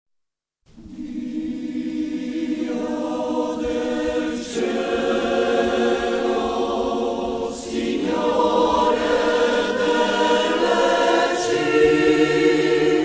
wersja koncertowa